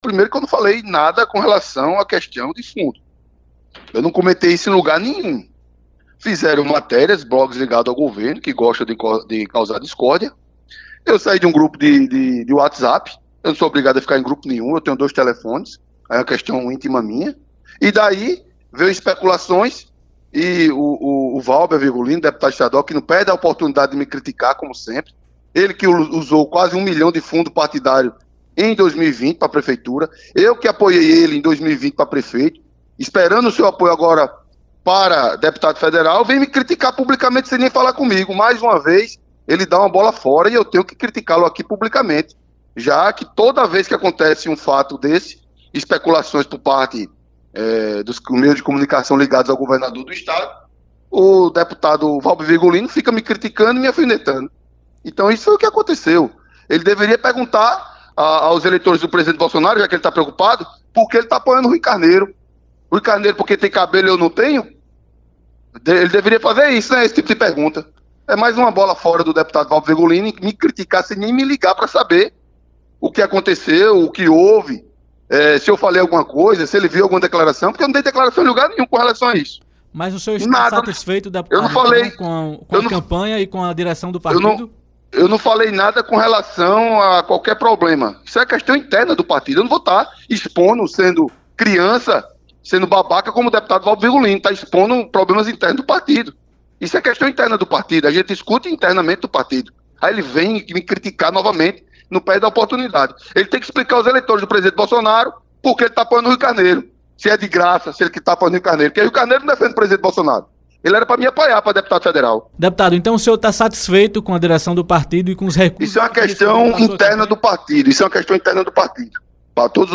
Em entrevista ao autor do blog, para a Rádio Arapuan FM, Wellington Roberto disse que ficou “surpreso” com as notícias sobre a suposta crise no partido e justificou que a legenda segue prazos para o envio de recursos para as candidaturas.